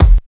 45 KICK 3.wav